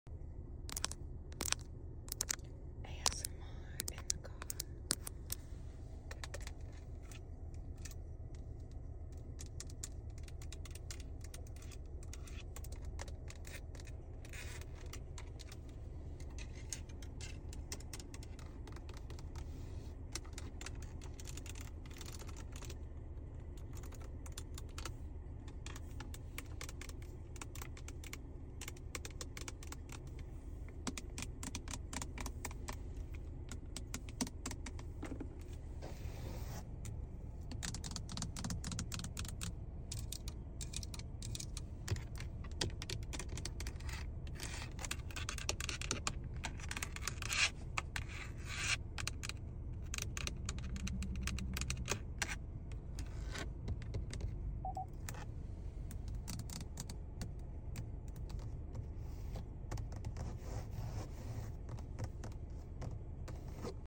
ASMR In The Car AGAIN Sound Effects Free Download